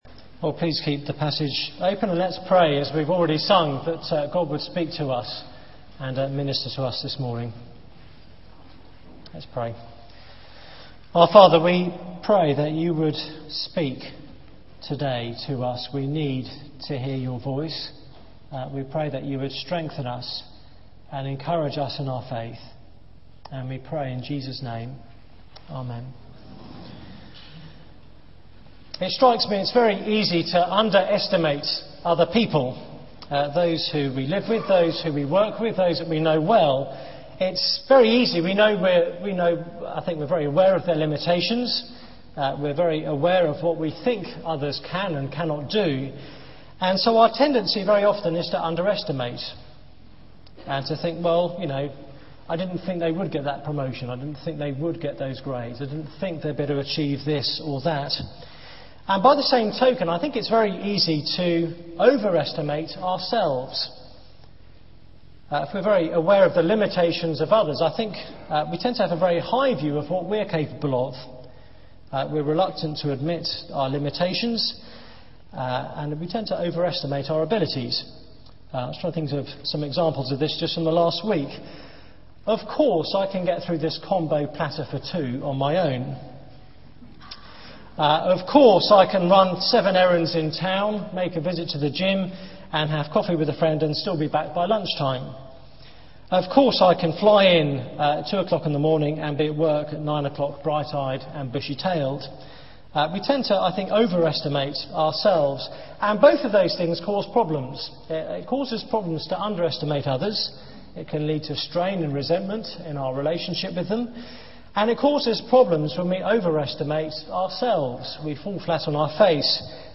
Media for 9:15am Service on Sun 05th Jul 2009 09:15 Speaker: Passage: Acts 9: 19 - 31 Series: Foundations for World Mission Theme: Authentic Faith There is private media available for this event, please log in. Sermon Search the media library There are recordings here going back several years.